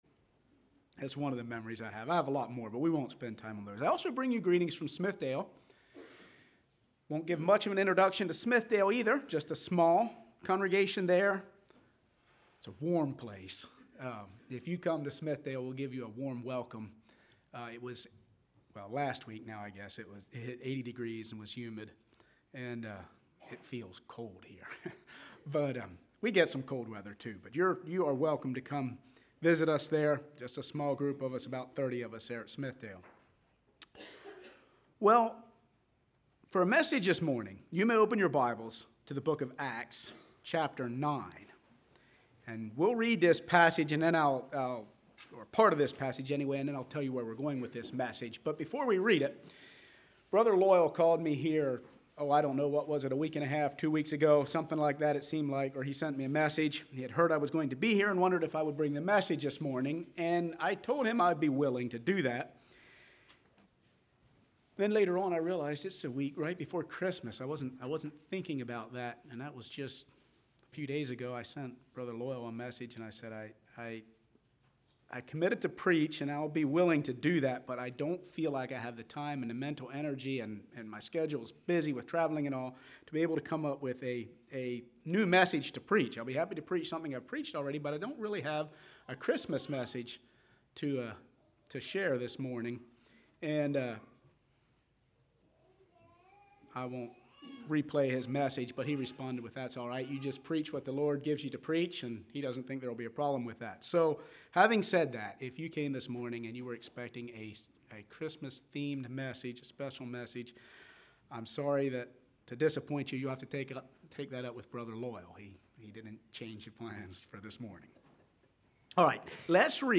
This sermon explores the transforming power of the gospel and how it applies to the 21st century followers of Christ.